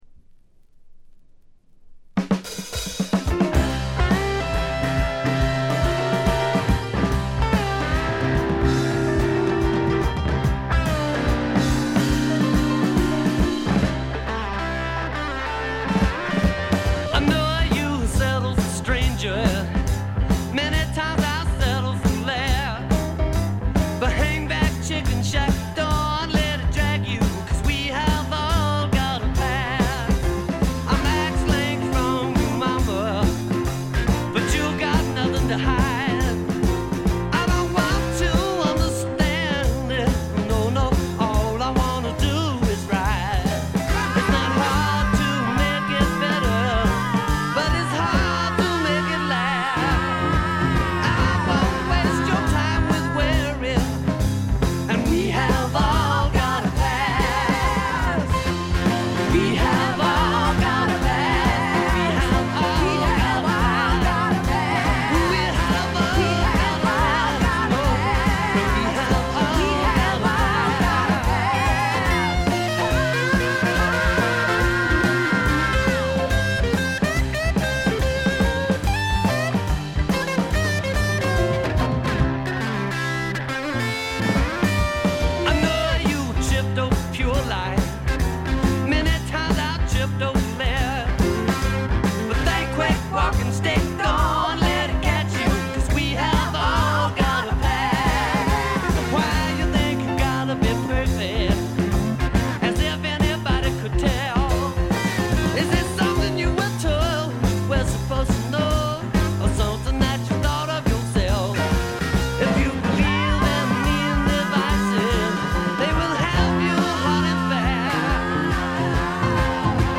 ほとんどノイズ感無し。
才気あふれるスワンプサウンドという感じ。
試聴曲は現品からの取り込み音源です。